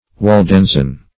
Search Result for " waldensian" : The Collaborative International Dictionary of English v.0.48: Waldensian \Wal*den"sian\, a. Of or pertaining to the Waldenses.